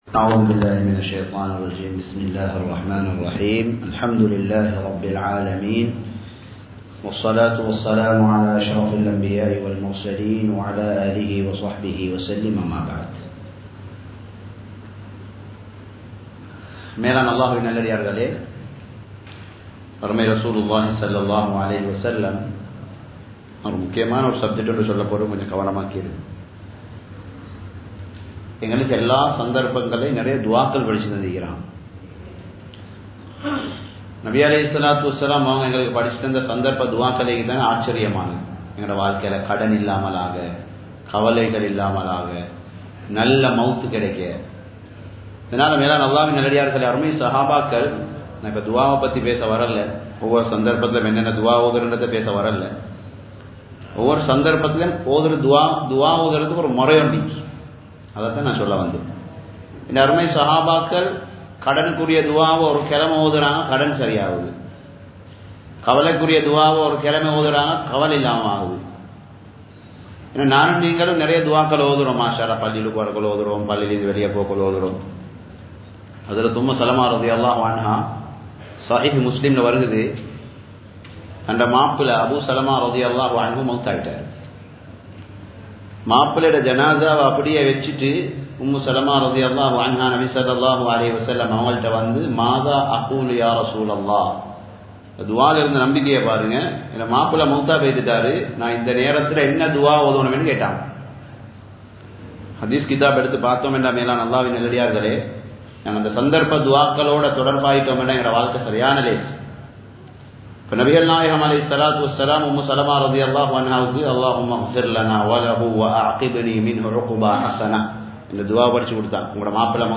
சந்தர்ப்ப துஆக்களின் சிறப்புகள் (பிக்ஹ் வகுப்பு) | Audio Bayans | All Ceylon Muslim Youth Community | Addalaichenai